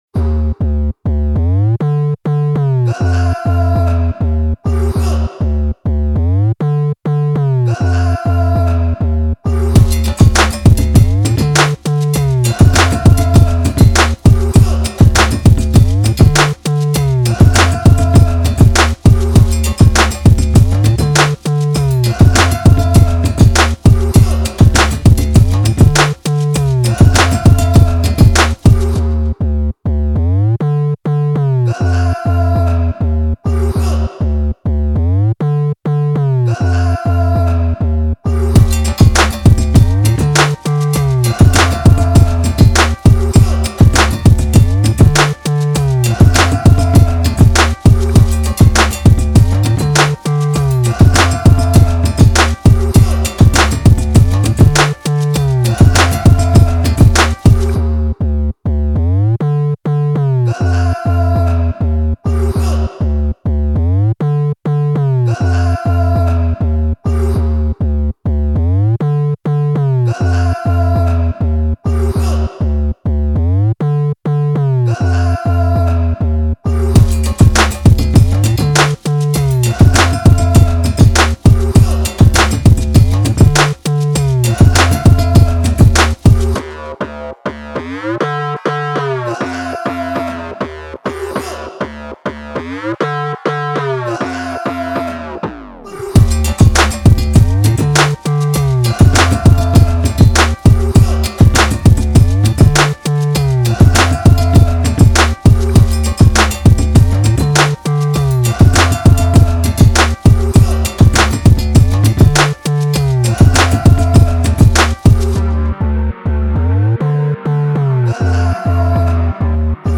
official instrumental
Rap Instrumentals